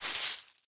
Minecraft Version Minecraft Version latest Latest Release | Latest Snapshot latest / assets / minecraft / sounds / block / leaf_litter / step3.ogg Compare With Compare With Latest Release | Latest Snapshot
step3.ogg